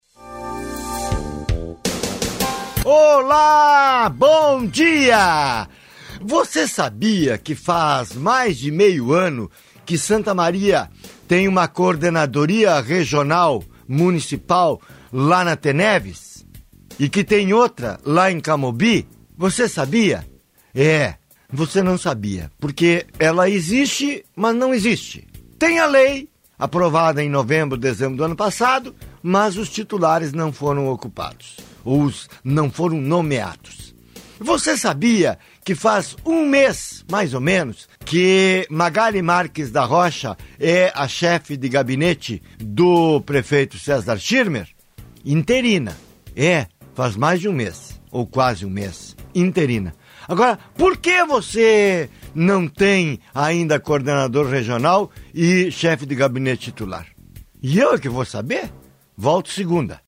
Com um minuto de duração, o comentário do editor, originalmente divulgado há meia hora, na Rádio Antena 1.